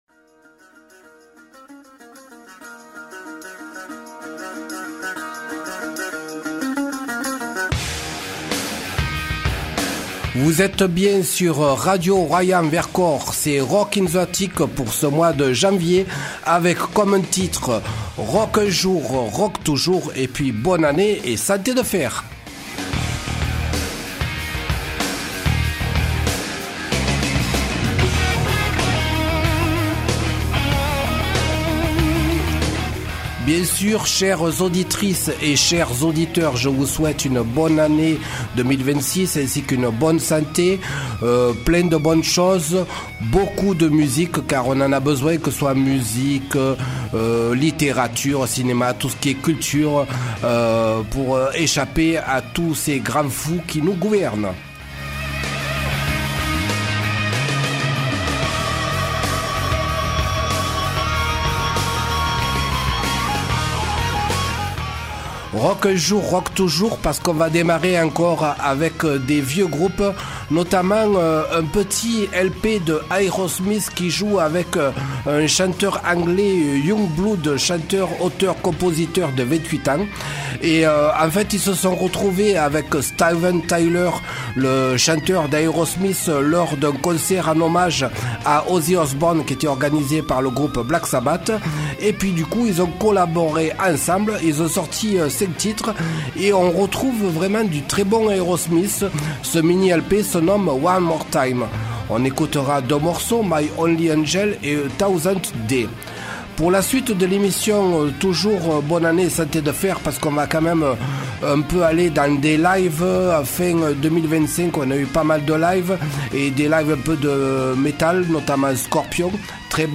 en live